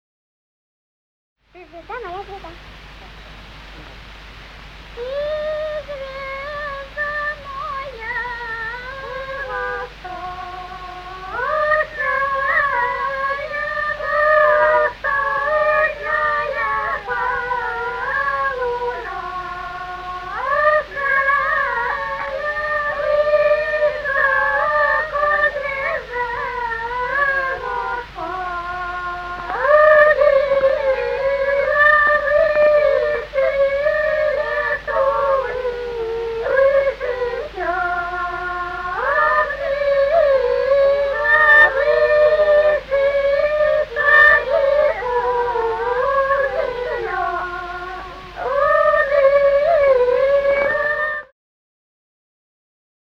Русские народные песни Владимирской области [[Описание файла::1. Ты, звезда моя восточная (свадебная) д. Галанино Судогодского района Владимирской области.